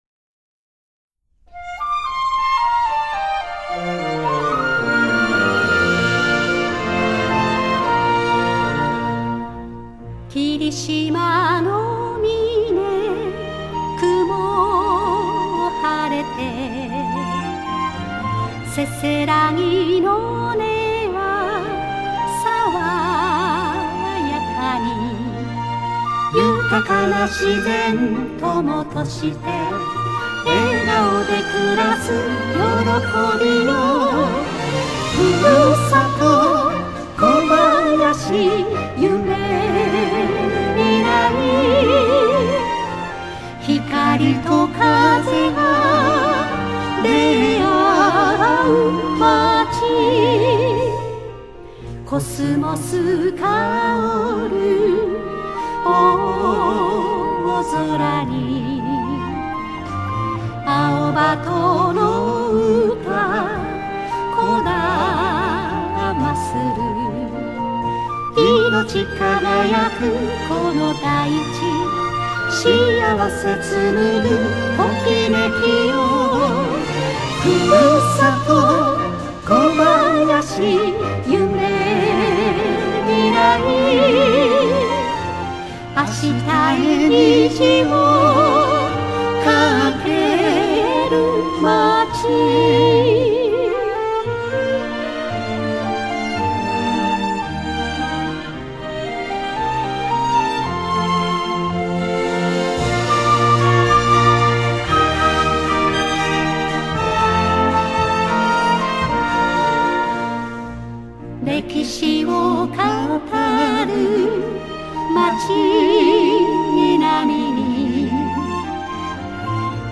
歌入り